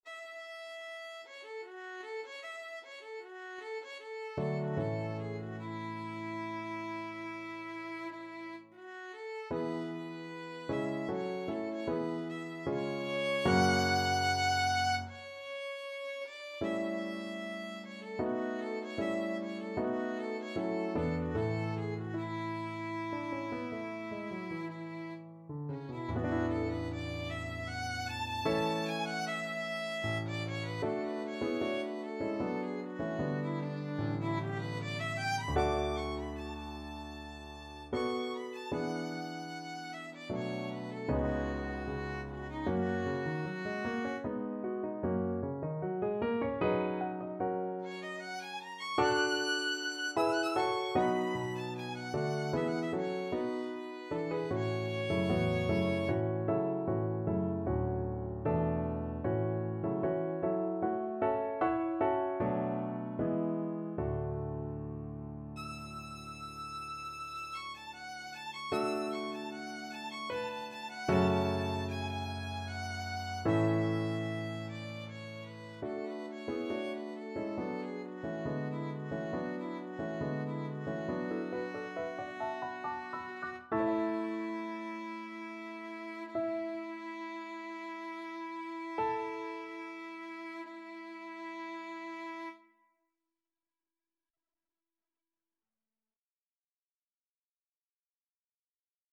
ViolinPiano
3/4 (View more 3/4 Music)
Trs calme et doucement expressif =76
Violin  (View more Intermediate Violin Music)
Classical (View more Classical Violin Music)